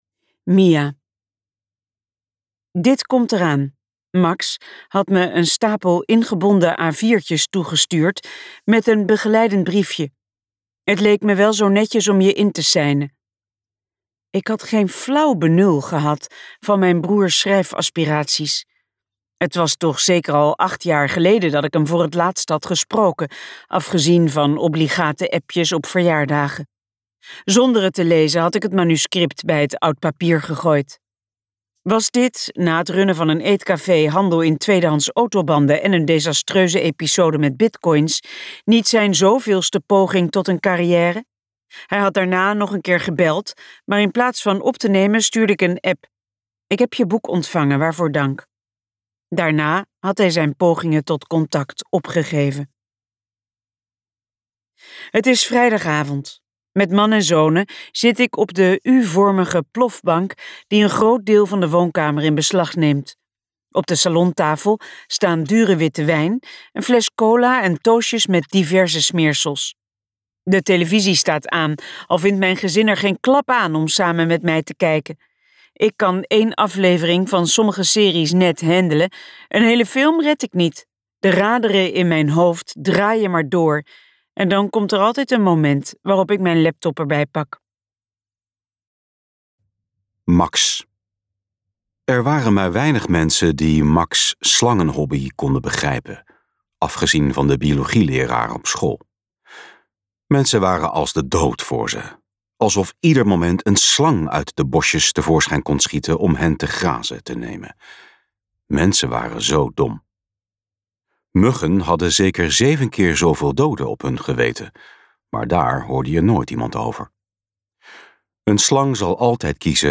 Jij bent het licht luisterboek | Ambo|Anthos Uitgevers